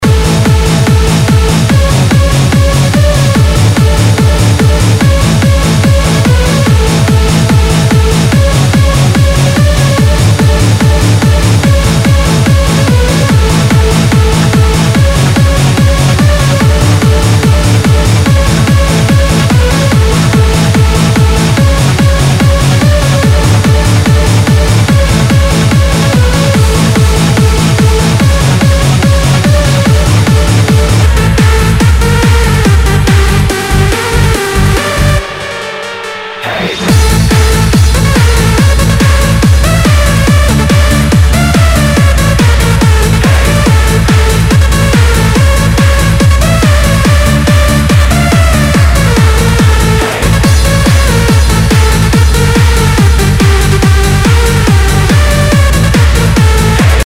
HOUSE/TECHNO/ELECTRO
ナイス！トランス！